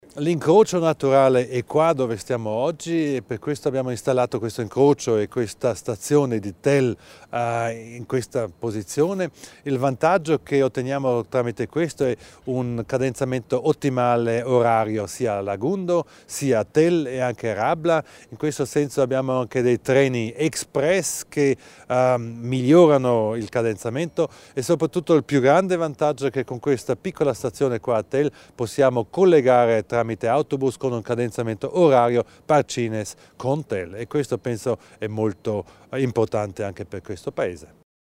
L'Assessore Thomas Widmann elenca i vantaggi della nuova stazione